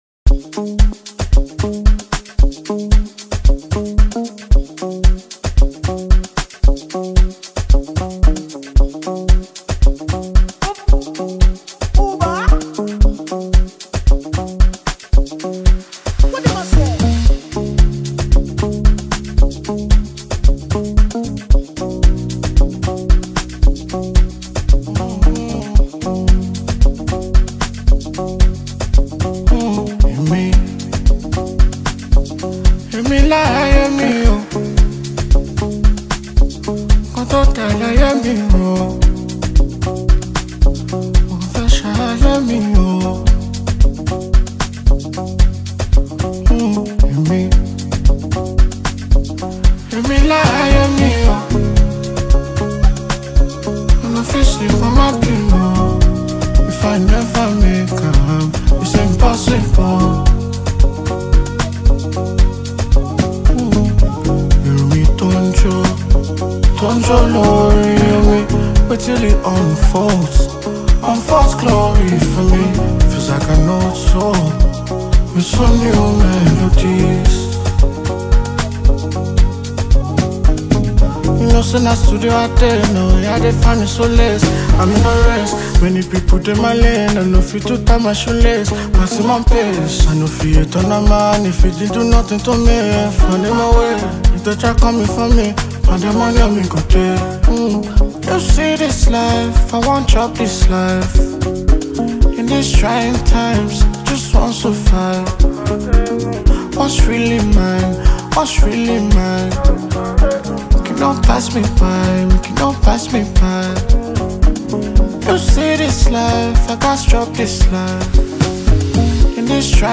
Multi-talented Nigerian singer